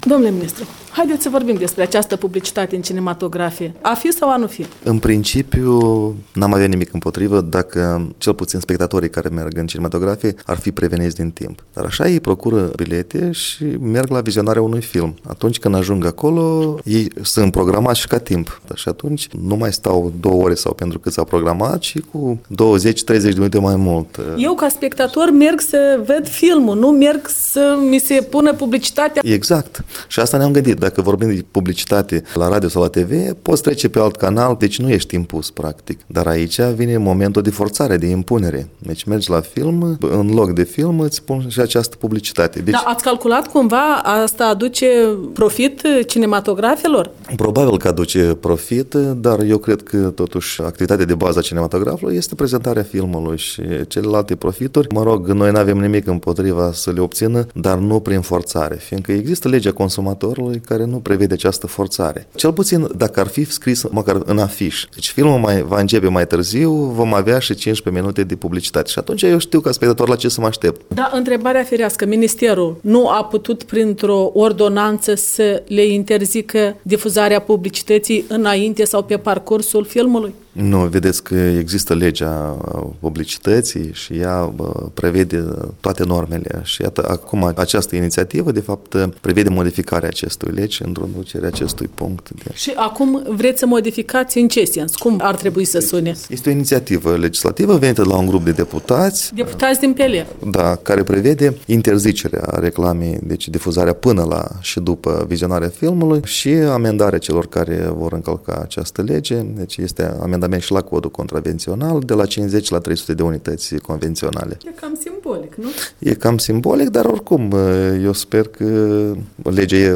Despre cinematografie în R. Moldova - de vorbă cu Boris Focșa ministrul culturii